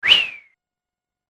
05 移動 動作 023 ピュー
ピュウ